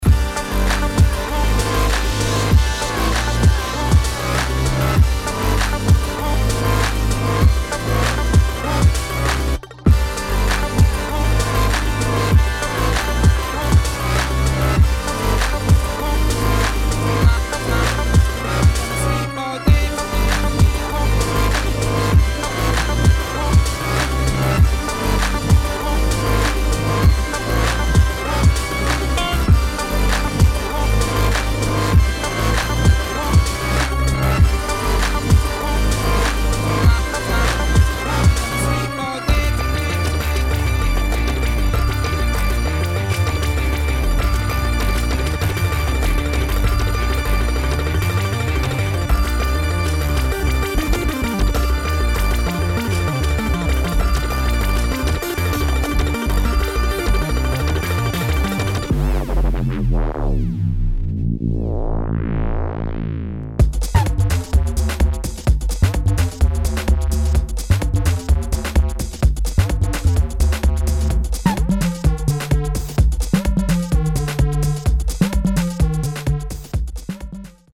[ BASS / TRAP / DOWNBEAT ]